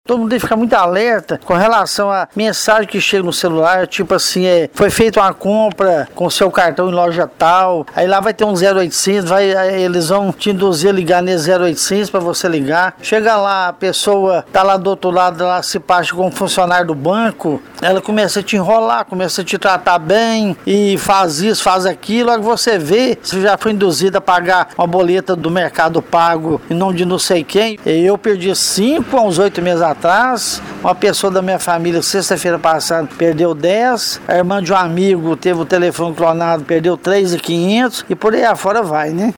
Trabalhador